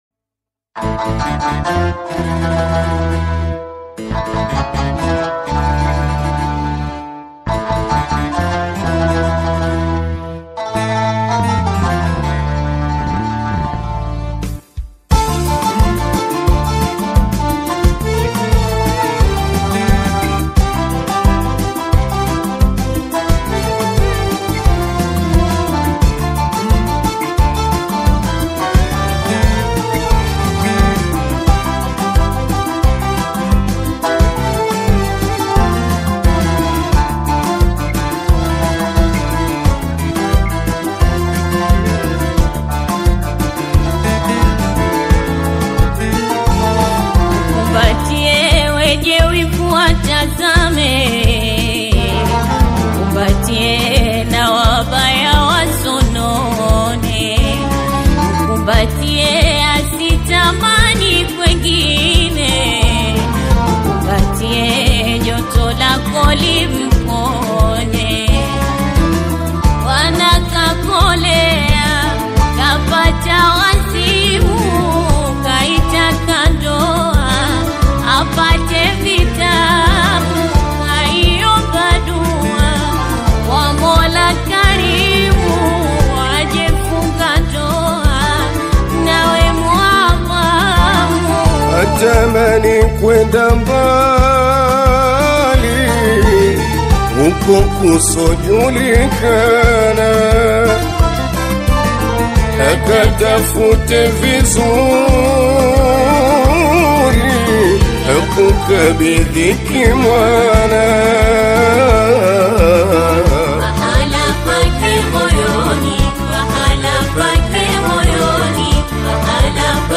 Tanzanian Bongo flava artist
a song with coastal rhythms